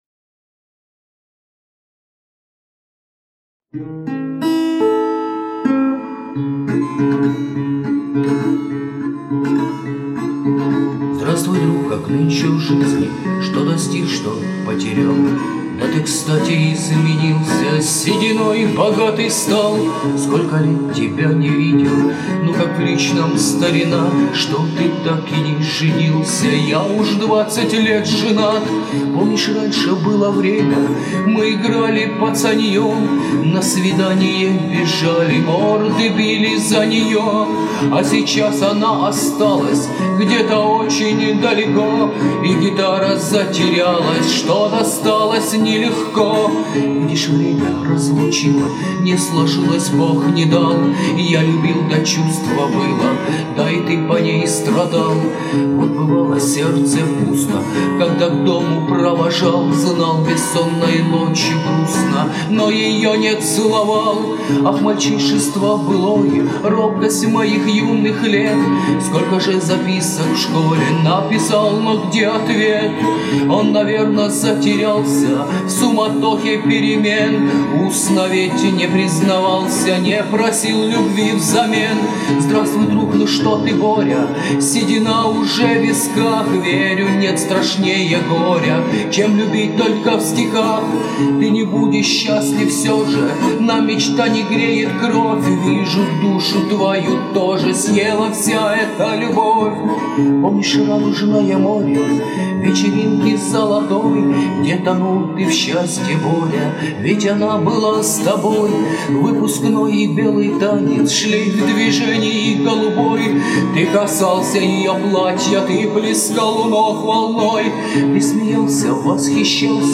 авторская песня под гитару о школьной любви